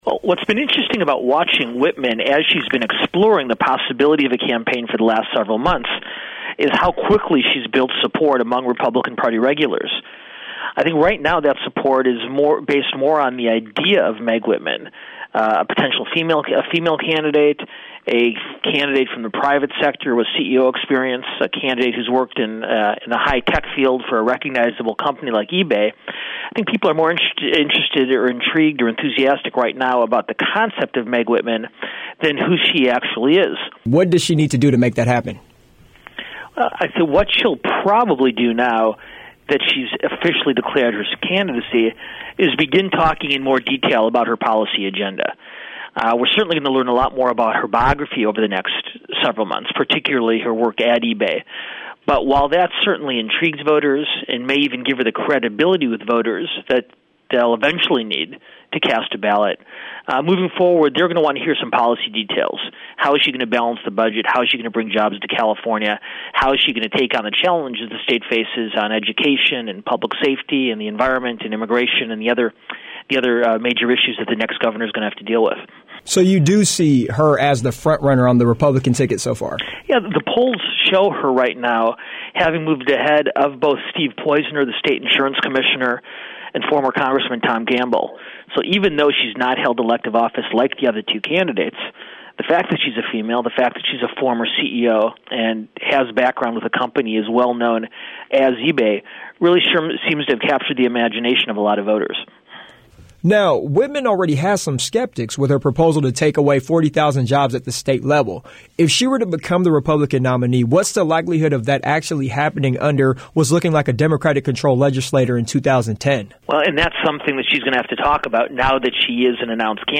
Interview | USC Annenberg Radio News